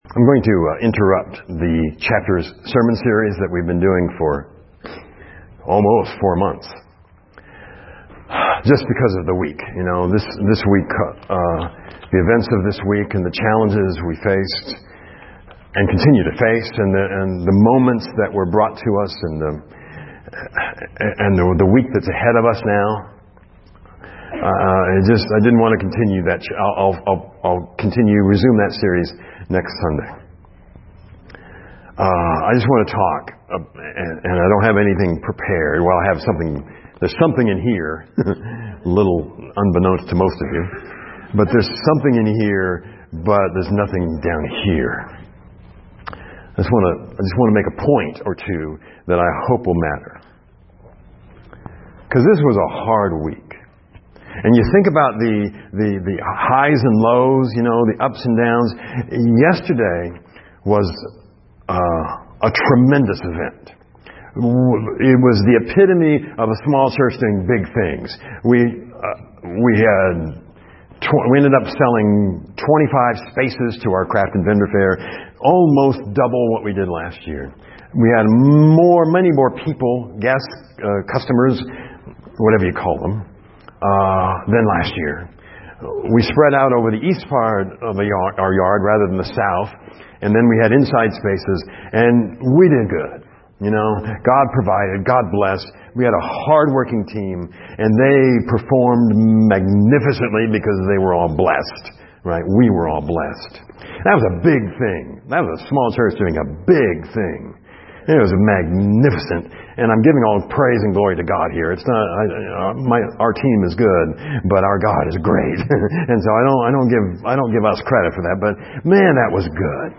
Sermon - 9-17-17.mp3